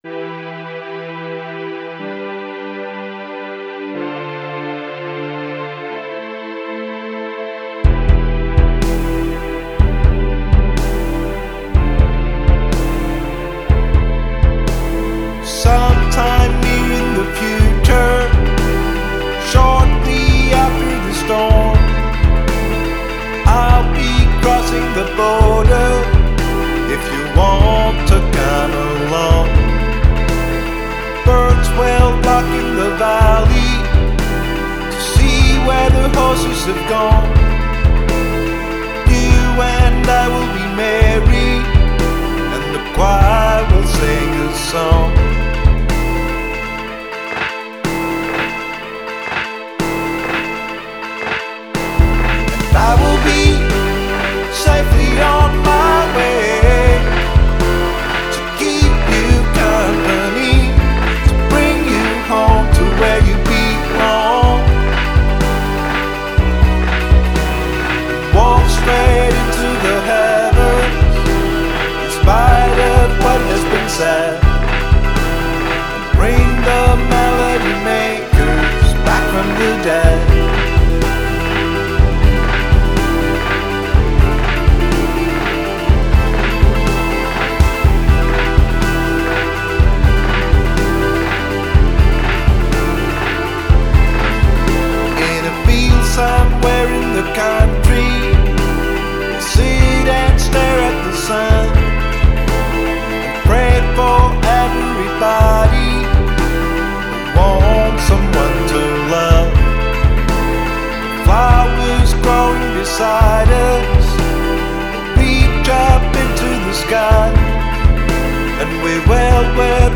Genre: Indie Rock, Alternative